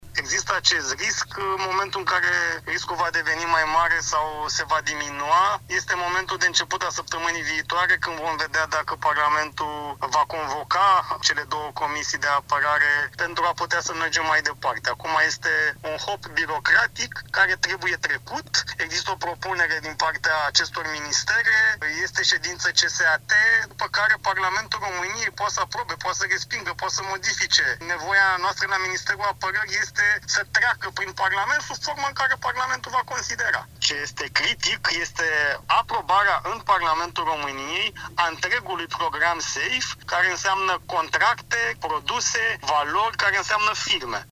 El spune la Europa FM că intenționează să ceară în Parlament un armistițiu pentru votarea celor 21 de proiecte, în valoare de 17 miliarde de euro.